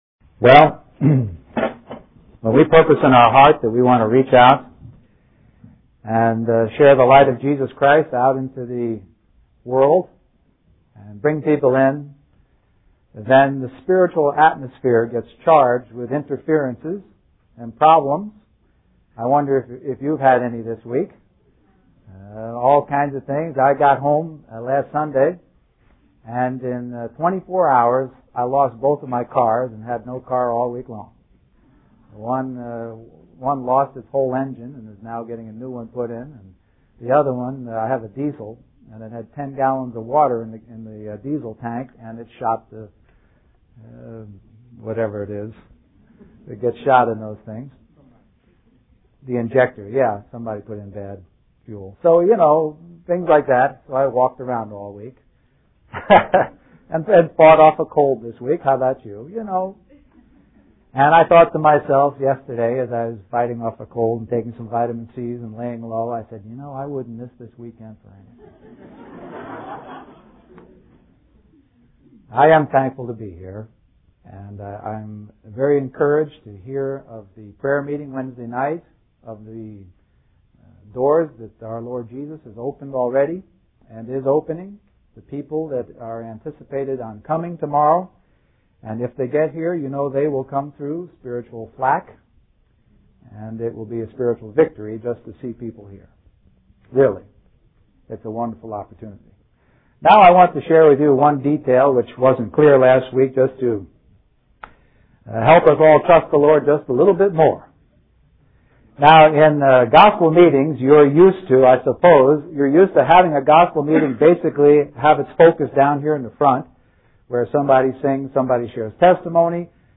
We apologize for the poor quality audio